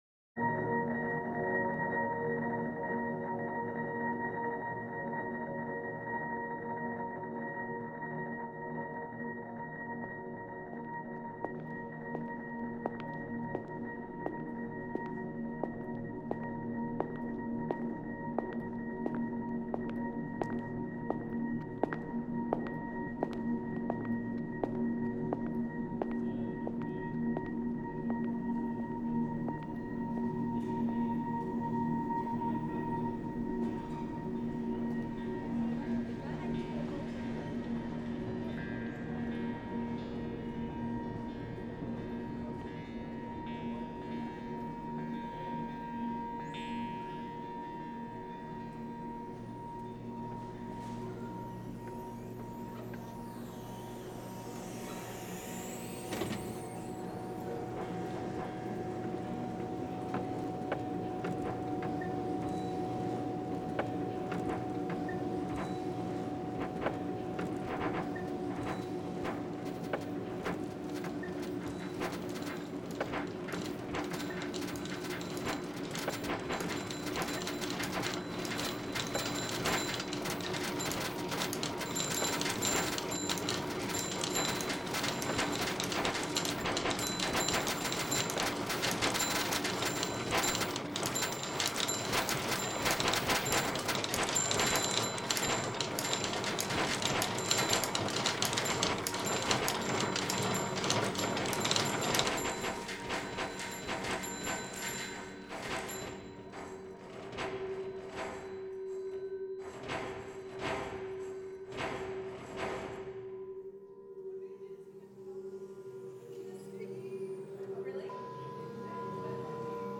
Compositional excerpts were created using a mixture of recognizable, real-world field recordings, processed recordings and synthetic pitched materials. Several excerpts exhibit phonographic approaches, while others involve sound-image transformations (i.e. sonic transmutation between two recognizable sounds) or interplay between synthetic pitched materials and concrète materials. The excerpts contained some repeating sounds — for instance, the sound of footsteps — while other sounds appeared only once.